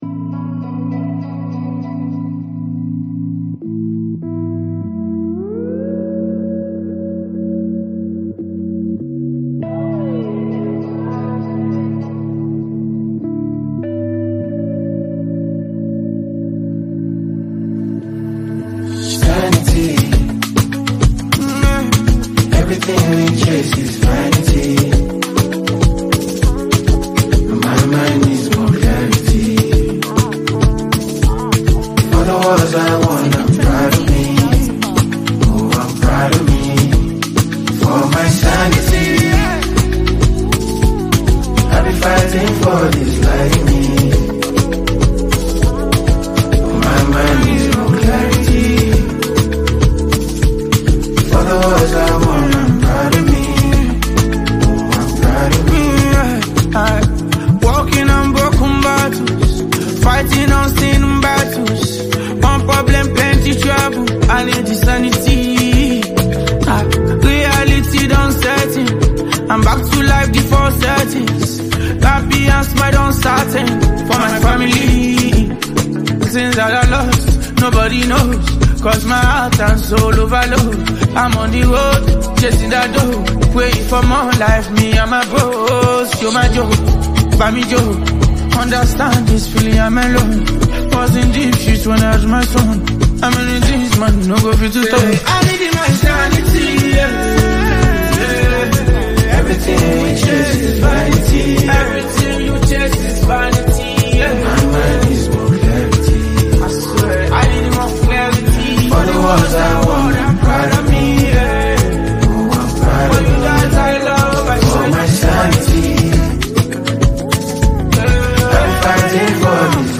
Renowned Nigerian Afrobeats talent and performer
It’s a song packed with depth, melody, and pure creativity.
The music scene is excited to embrace this energetic release